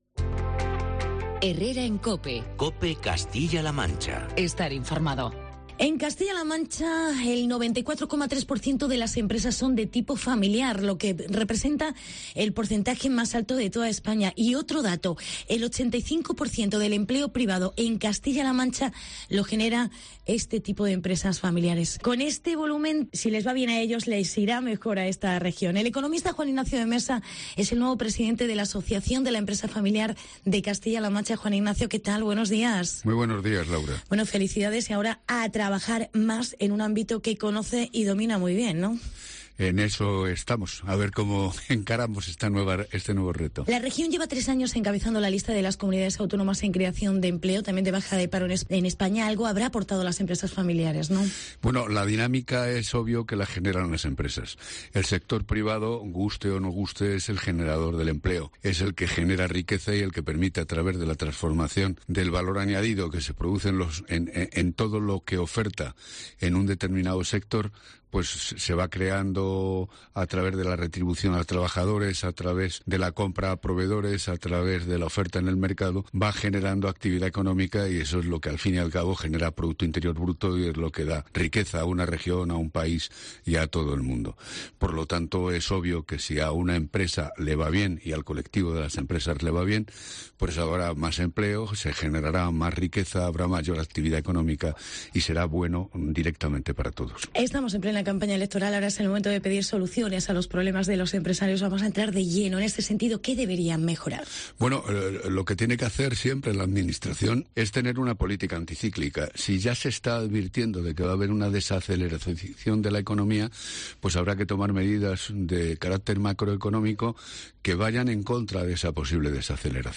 El 94,3% de las empresas en CLM son de tipo familiar. Entrevista